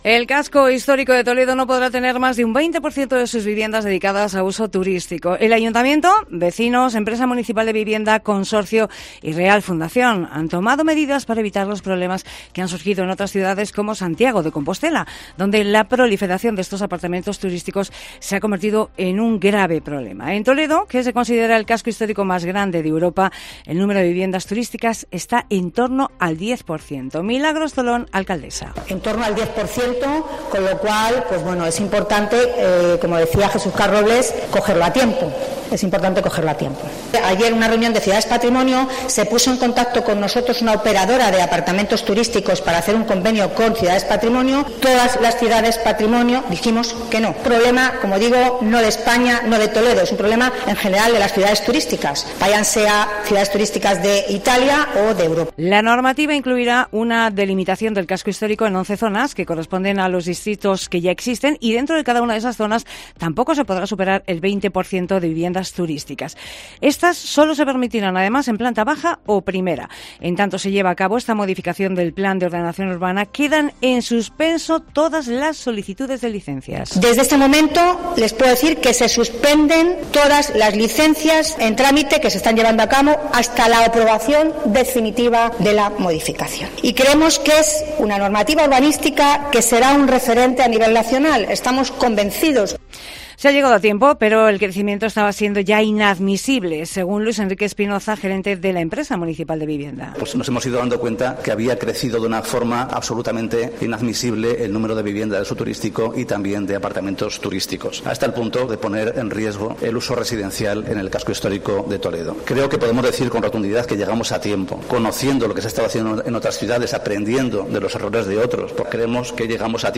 Lo ha contado la alcaldesa, Milagros Tolón, en una rueda de prensa a la que han asistido representantes de todas las partes implicadas.